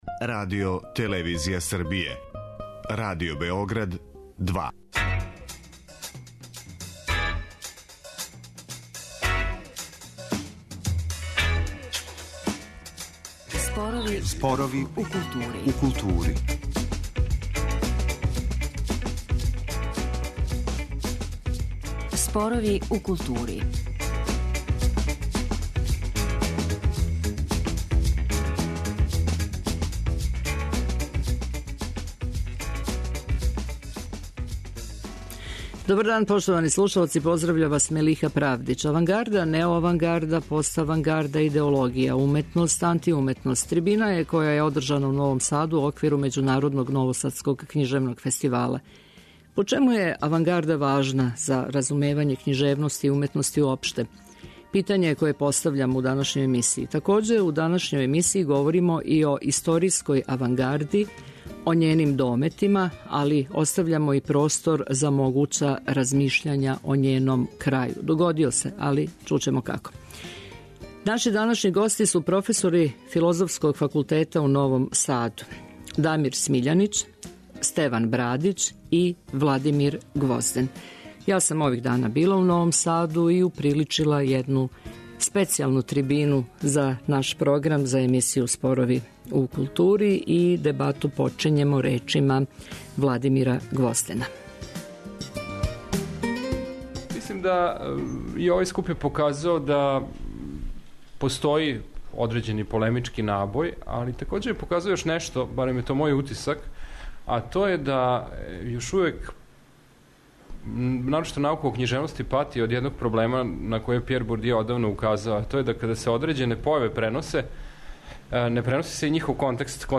'Авангарда, неоавангарда, поставангарда, идеологија, уметност, антиуметност' - трибина је која је одржана у Новом Саду у оквиру Међународног новосадског књижевног фестивала.